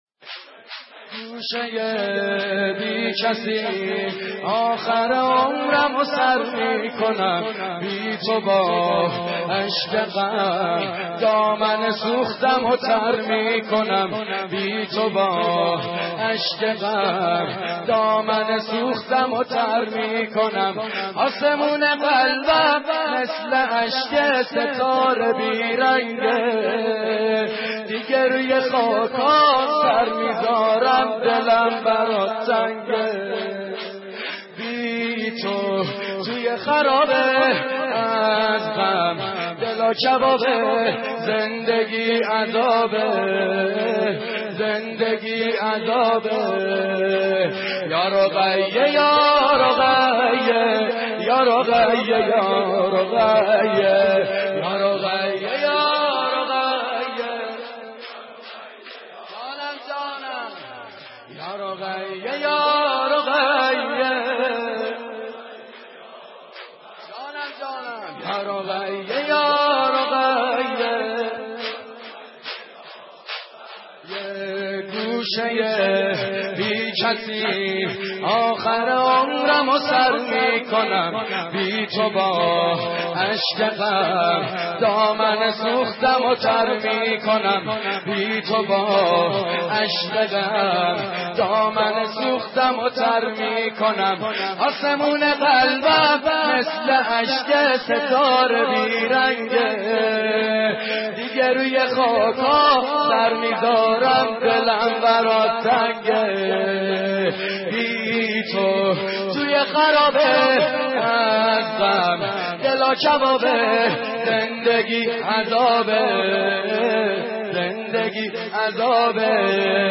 دانلود مداحی بی تو با اشک غم - دانلود ریمیکس و آهنگ جدید
نوحه خوانی حاج سعید حدادیان به مناسبت شهادت حضرت زهرا(س) (3:38)